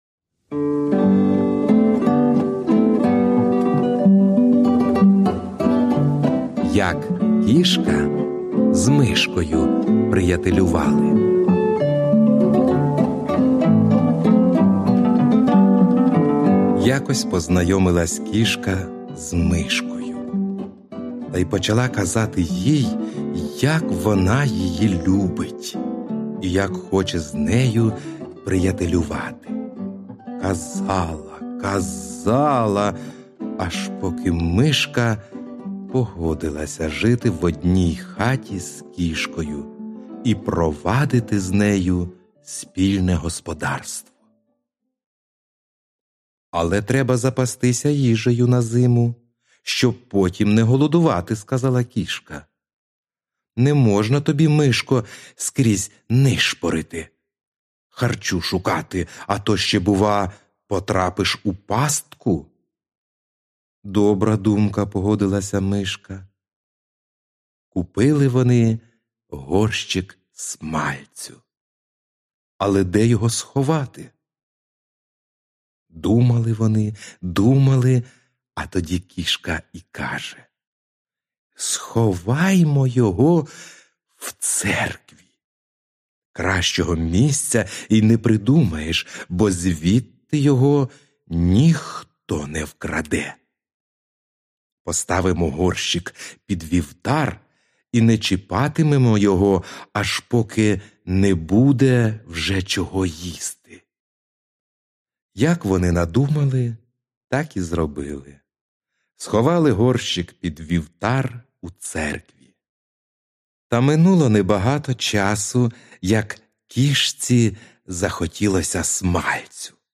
Аудіоказка Як кішка з Мишкою приятелювалиь
Жанр: Літературна / Навчання / Гумор